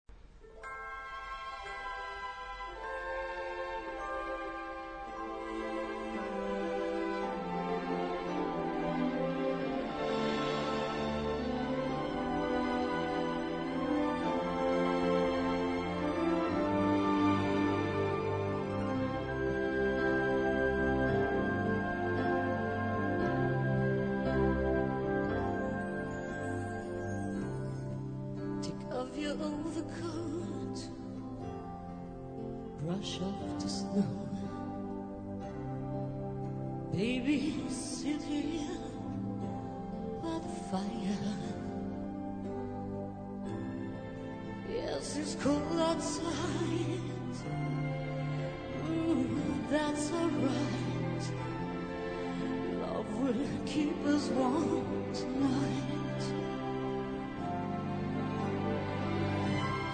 key: C-major